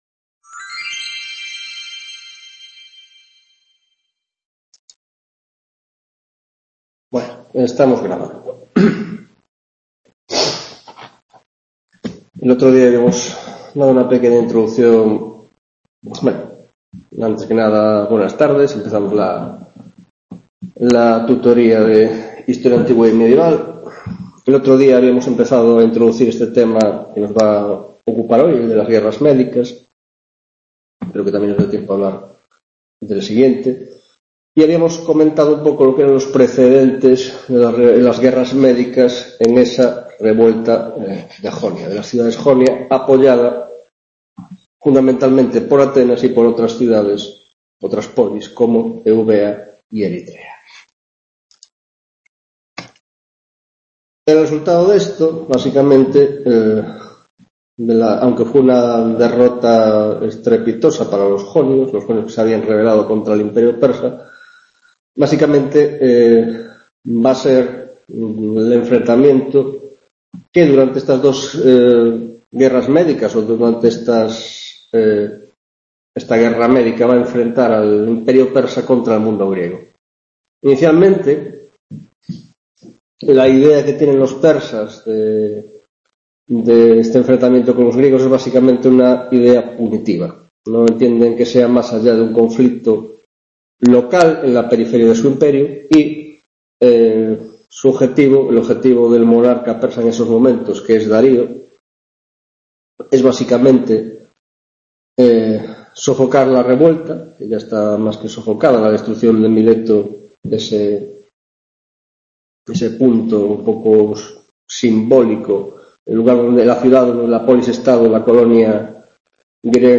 3ª Tutoria de Historia Antigua y Medieval del Grado de Filosofia: Guerras Médicas (1ª Parte) y Pentecontecia (Introducción)